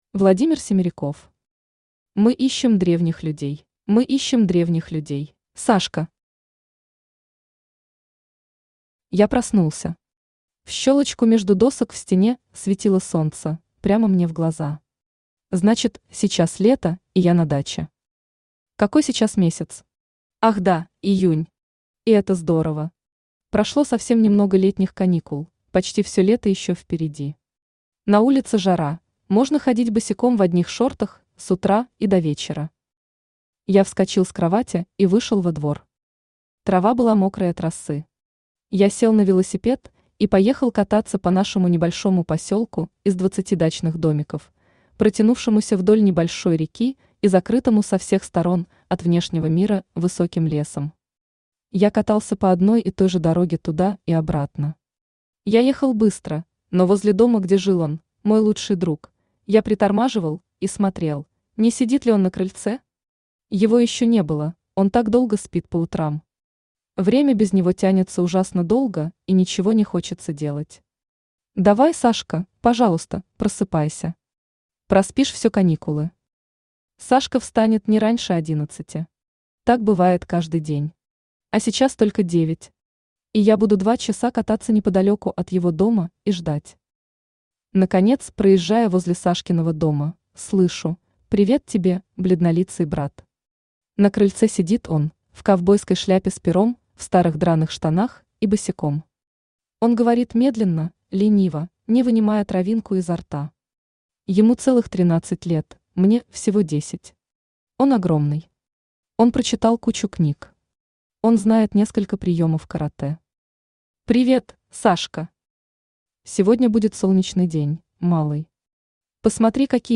Aудиокнига Мы ищем древних людей Автор Владимир Семериков Читает аудиокнигу Авточтец ЛитРес.